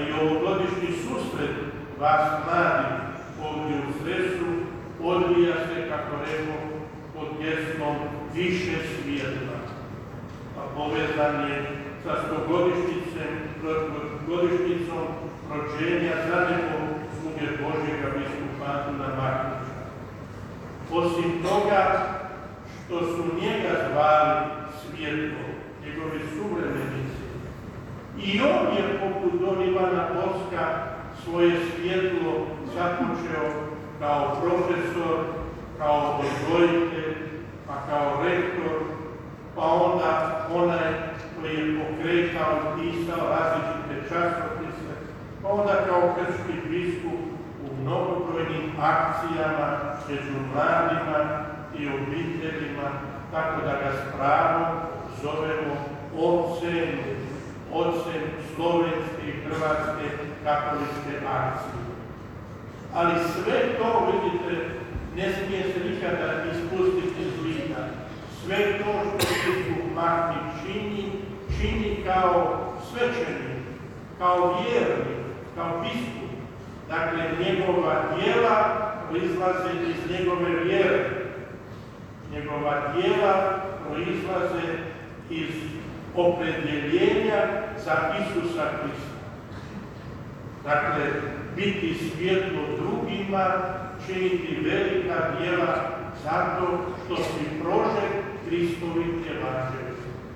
U propovijedi je biskup Petanjak istaknuo: „Svaki put kad ste nekoga iščupali iz njegovog problema, vi ste mu upalili svijetlo.